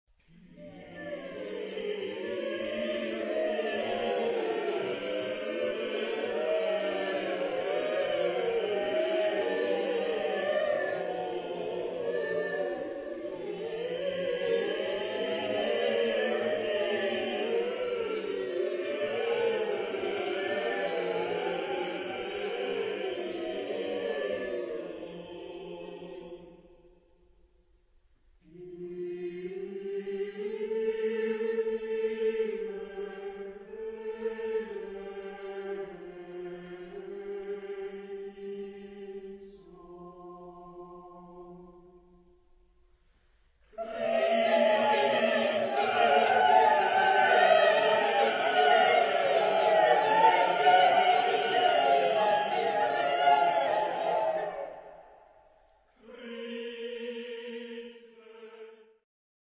Género/Estilo/Forma: Sagrado
Tipo de formación coral: SSATB  (5 voces Coro mixto )